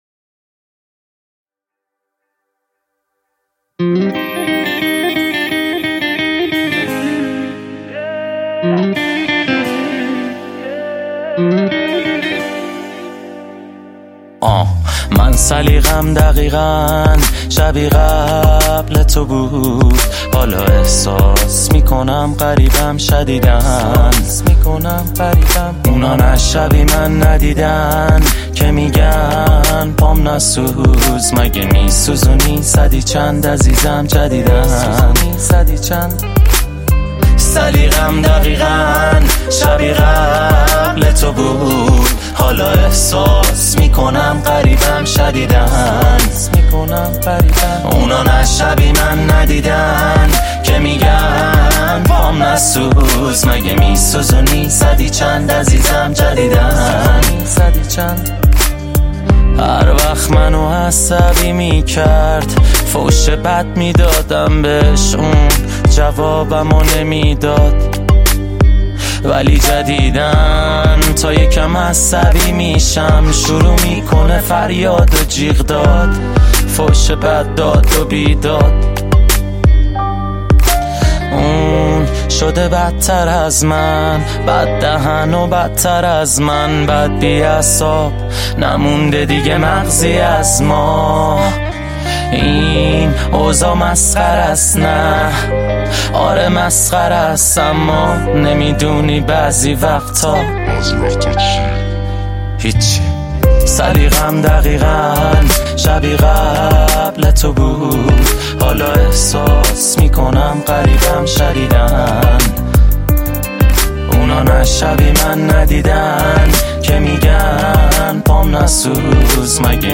موزیک و اصلاح اهنگ های رپ